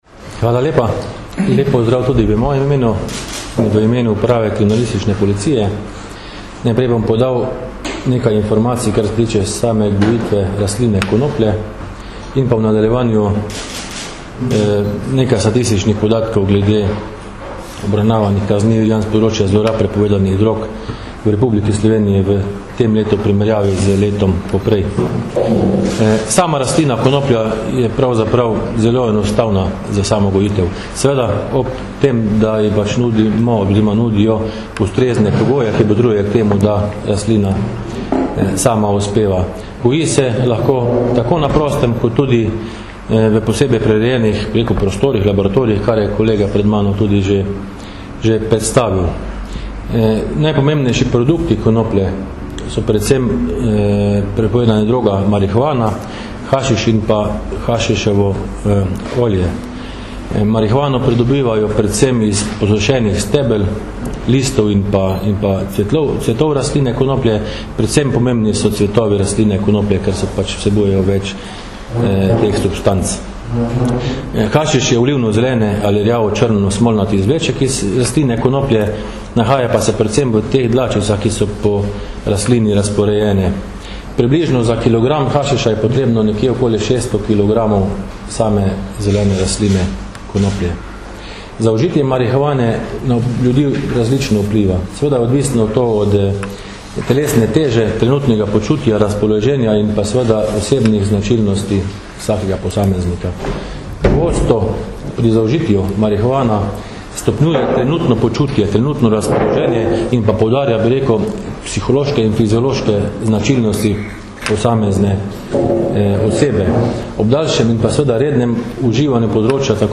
Policija - Razkrili kriminalno združbo preprodajalcev marihuane - informacija z novinarske konference